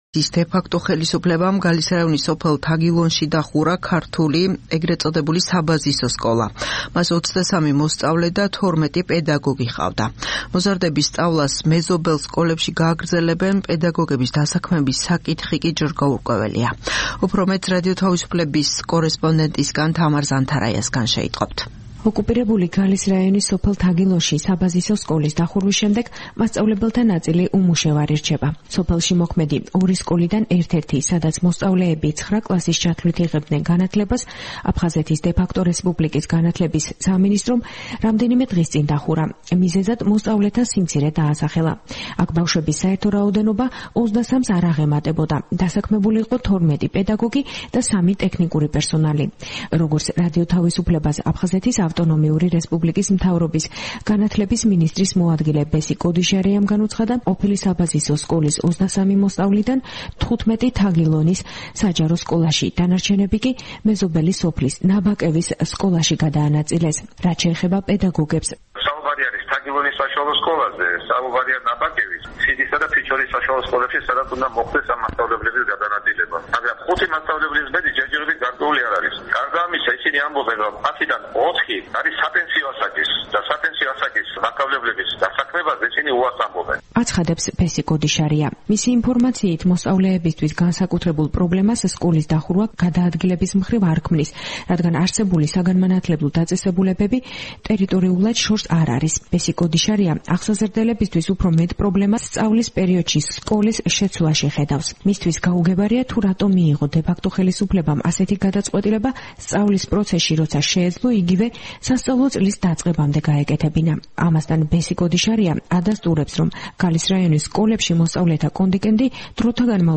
აფხაზეთის მთავრობის წარმომადგენლის ამ აზრს არ ეთანხმება გალის სოფელ ნაბაკევში მცხოვრები რადიო თავისუფლების რესპონდენტი. ქალბატონი, რომლის ვინაობასაც მისივე თხოვნით არ ვასახელებთ, სატელეფონო საუბარში ამბობს, რომ სკოლებში კონტინგენტის შემცირება რეგიონში არსებულ მძიმე სოციალურ პირობებს უკავშირდება.